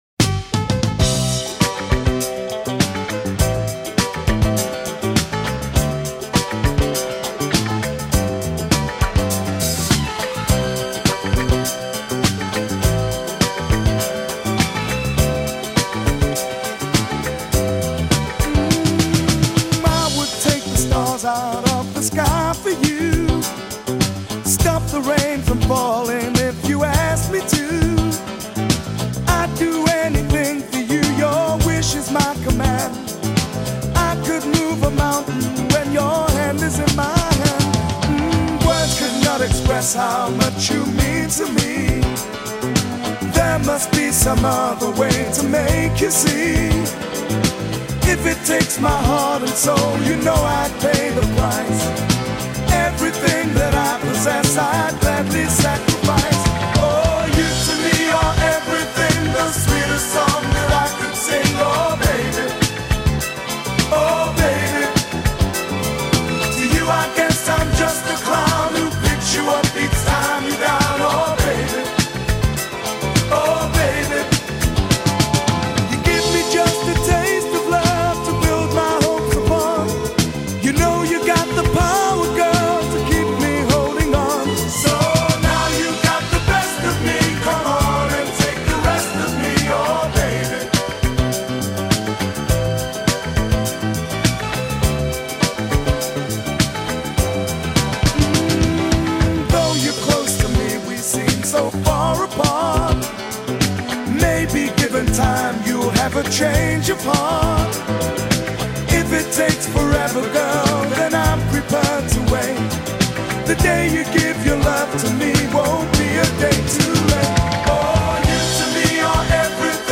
With this pop/soul classic